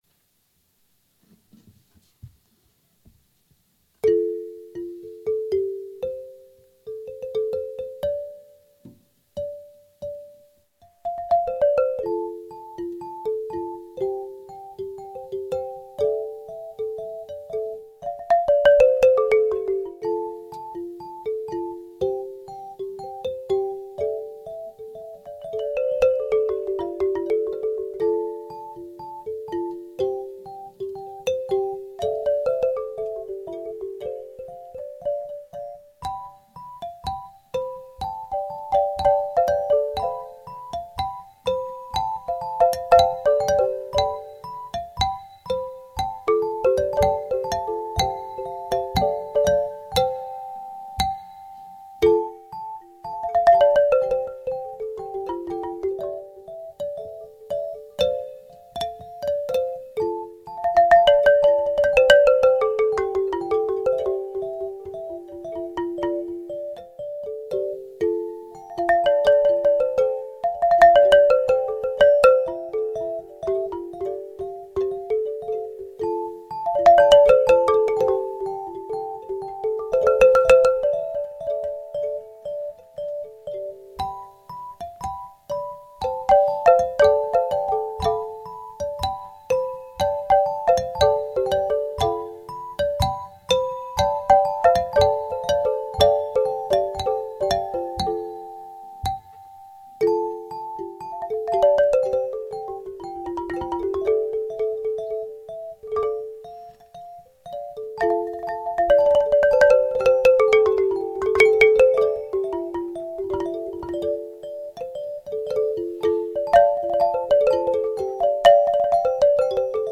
Argilophones : la terre qui chante